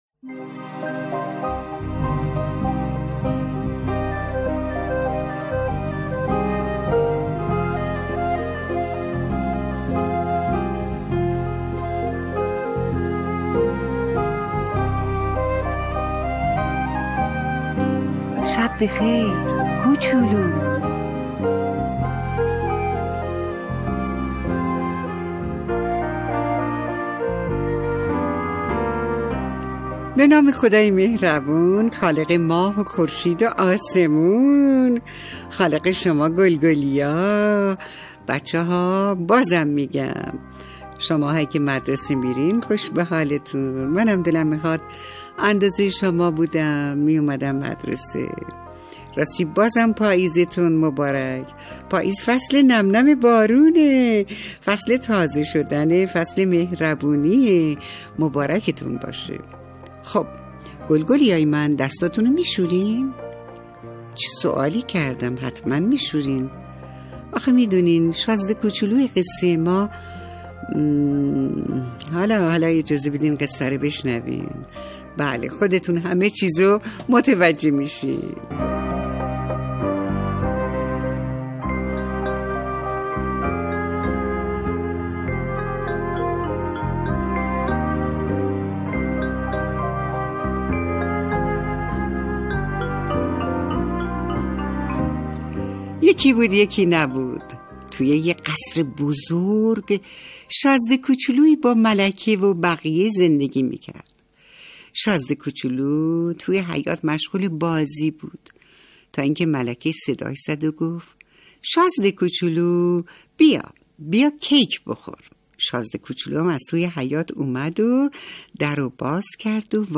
قصه صوتی کودکان دیدگاه شما 13,755 بازدید